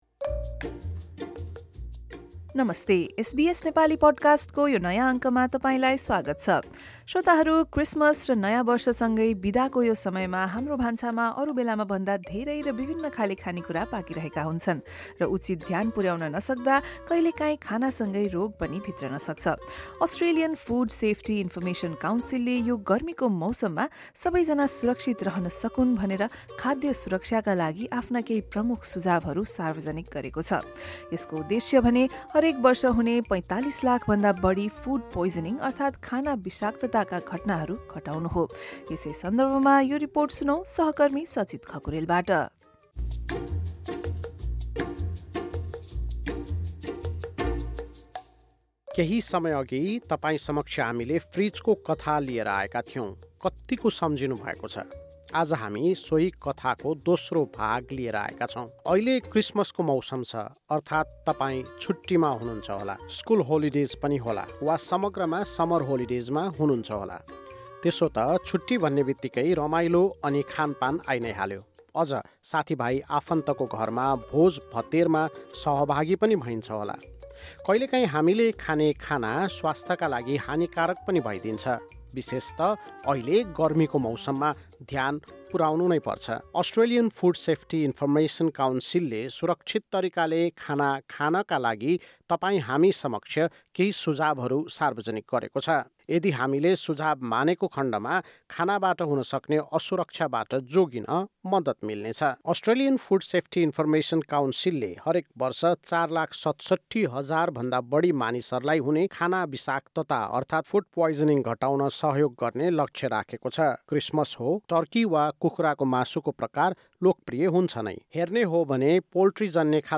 अस्ट्रेलियन फुड सेफ्टी इन्फर्मेसन काउन्सिलले यो गर्मीको मौसममा खाद्य सुरक्षाका लागि आफ्ना सुझावहरू सार्वजनिक गरेको छ। यसको उद्देश्य हरेक वर्ष हुने ४५ लाखभन्दा बढी ‘फुड पोइजनिङ’ अर्थात् खाना विषाक्तताका घटनाहरू घटाउनु हो। एक रिपोर्ट।